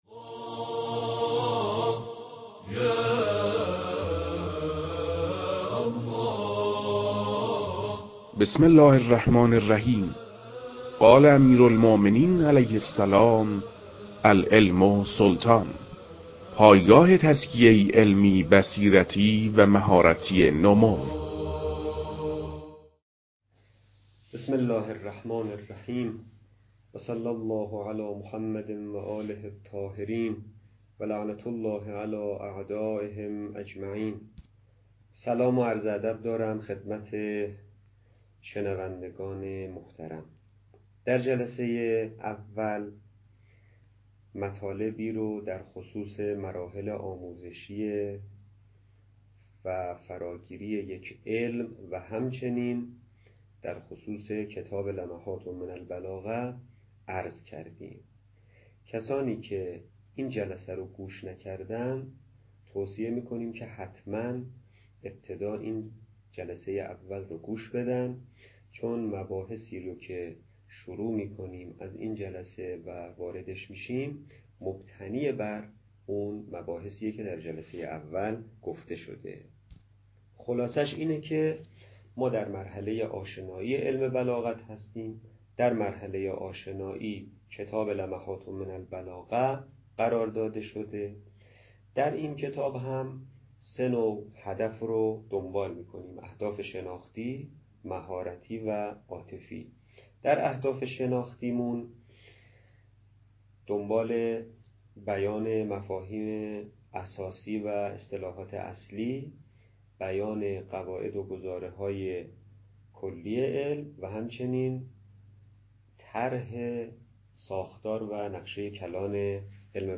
در این بخش، کتاب «لمحات من البلاغة» که اولین کتاب در مرحلۀ آشنایی با علم بلاغت است، به صورت ترتیب مباحث کتاب، تدریس می‌شود.
در تدریس این کتاب- با توجه به سطح آشنایی کتاب- سعی شده است، مطالب به صورت روان و در حد آشنایی ارائه شود.